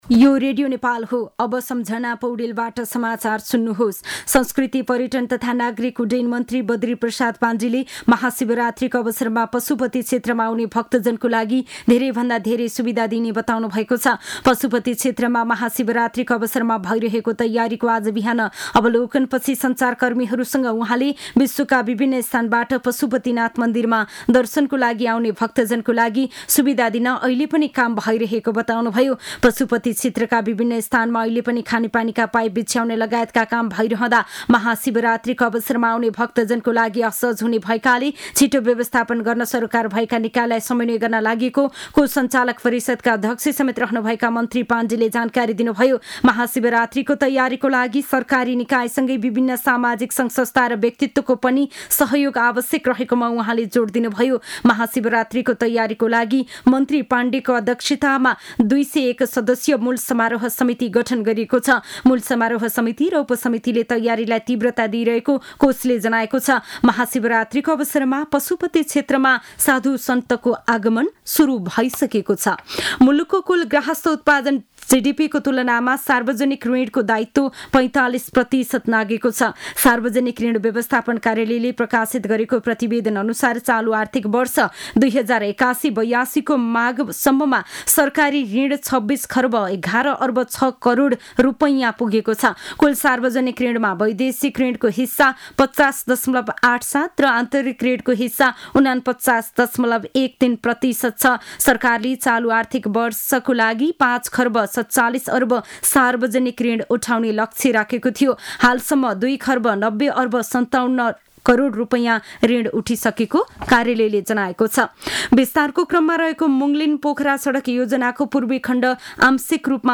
दिउँसो १ बजेको नेपाली समाचार : १० फागुन , २०८१
1-pm-news-1-8.mp3